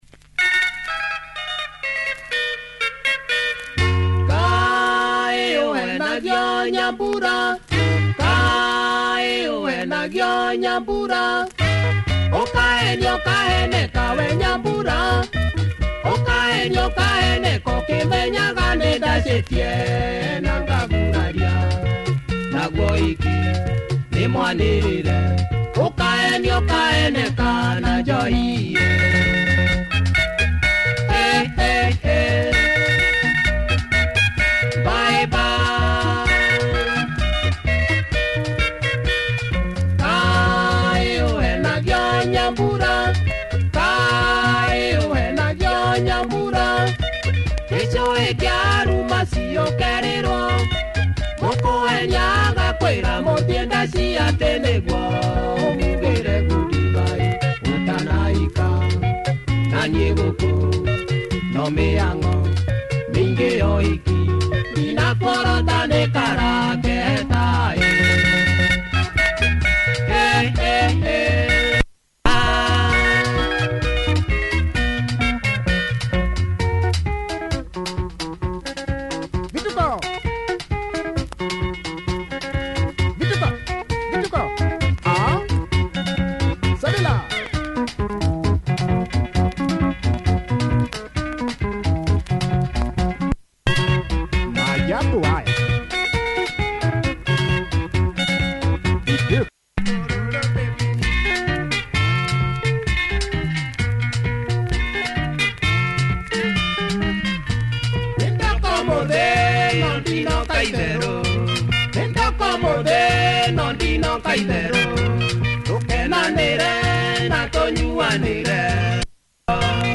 Kikuyu Benga